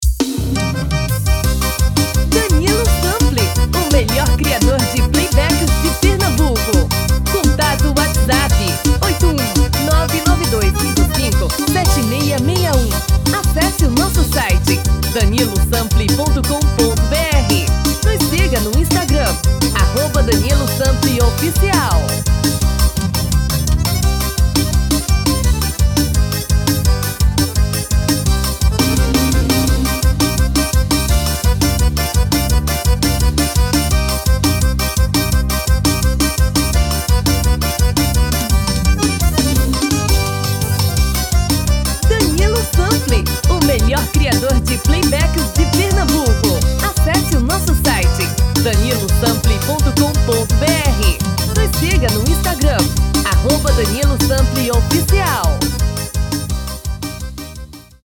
DEMO 1: tom original / DEMO 2: tom feminino